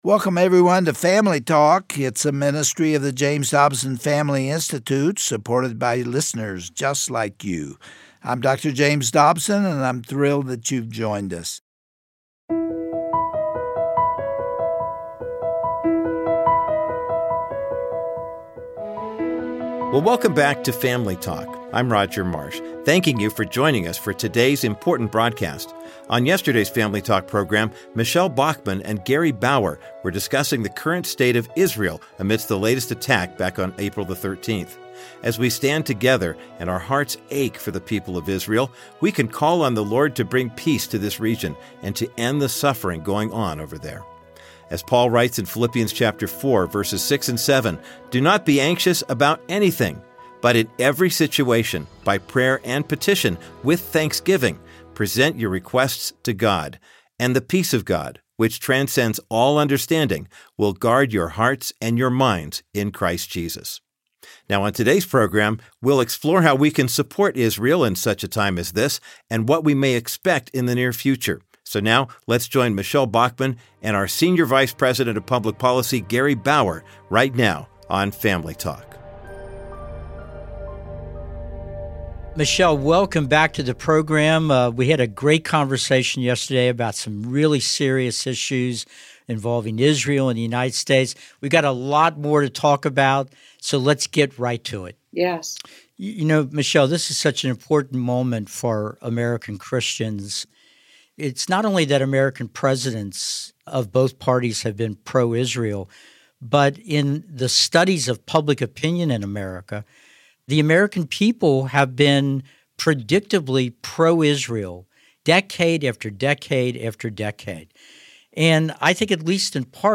On today’s edition of Family Talk, Gary Bauer and the Hon. Michele Bachmann conclude their discussion on the continued war in Israel.